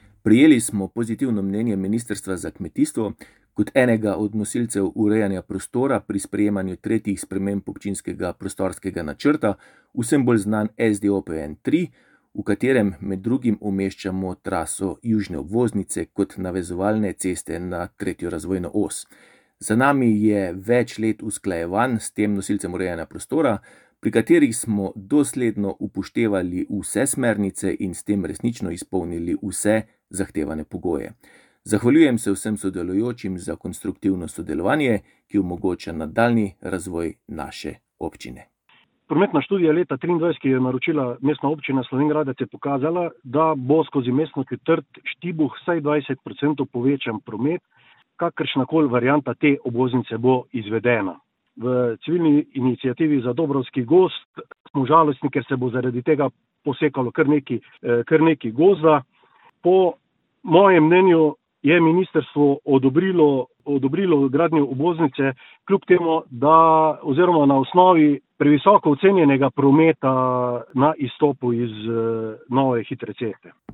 Poslušajte oba sogovornika: